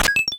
Super Mario Maker - SMB & SMB3 Door Unlock
SMM_SMB_Door_Unlock.oga